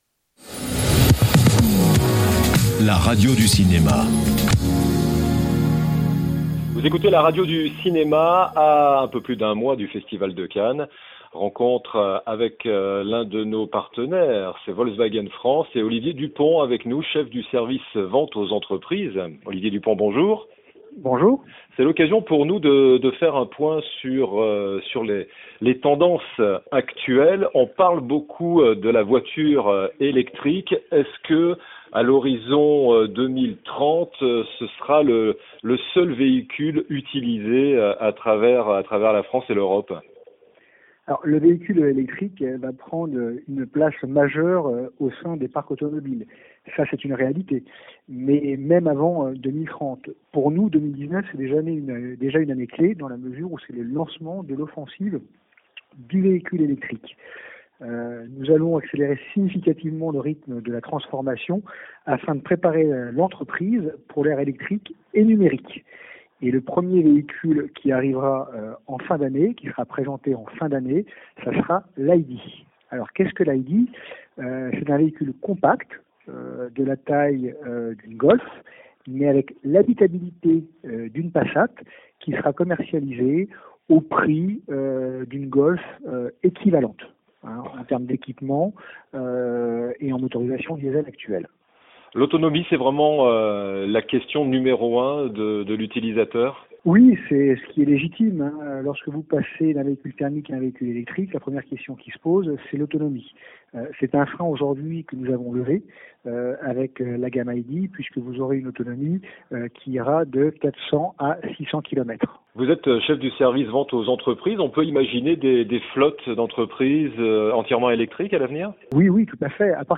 A quelques semaines du Festival de Cannes, La Radio du Cinéma tend le micro à son partenaire Volkswagen.